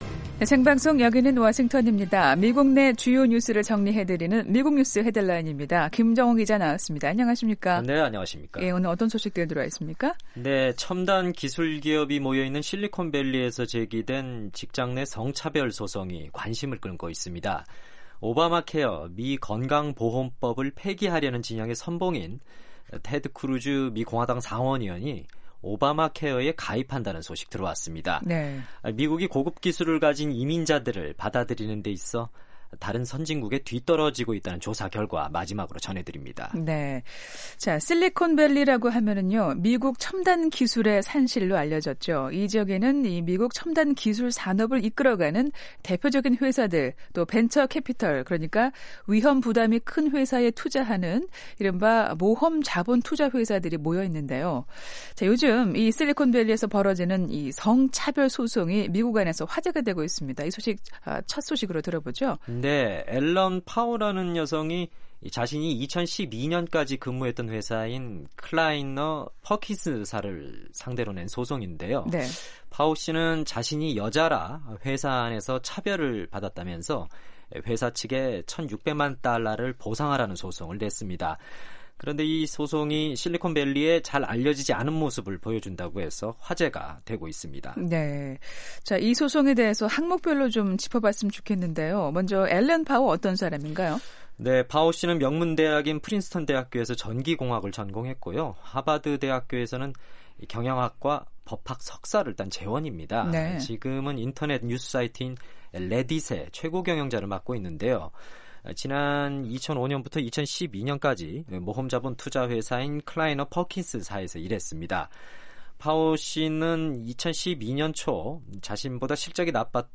미국 내 주요 뉴스를 정리해 드리는 ‘미국 뉴스 헤드라인’입니다. 첨단 기술 기업이 모여 있는 실리콘밸리에서 제기된 직장 내 성차별 소송이 관심을 끌고 있습니다.